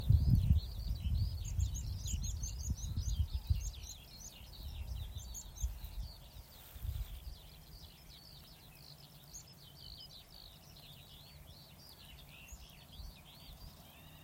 Pļavu čipste, Anthus pratensis